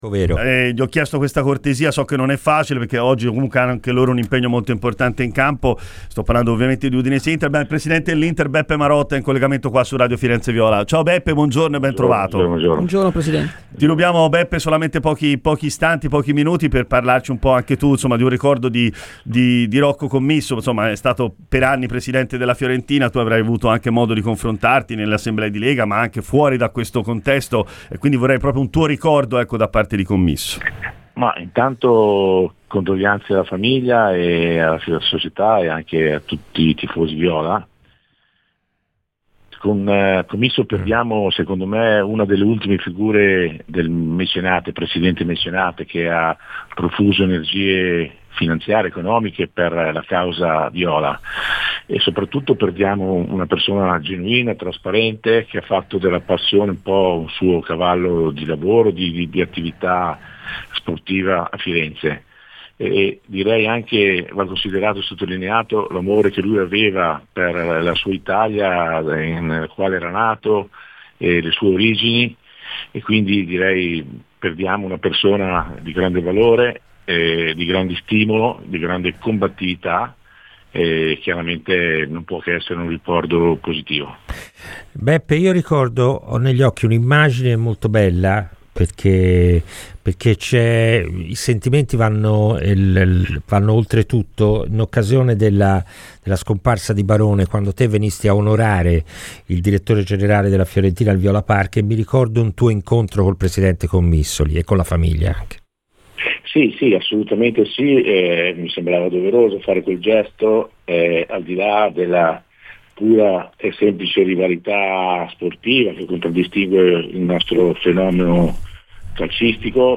Il presidente dell'Inter Giuseppe Marotta è intervenuto a Radio FirenzeViola durante la lunga diretta speciale per la morte di Rocco Commisso.